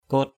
/ko:t/ (d.) ức, một trăm ngàn = cent mille. one hundred thousand.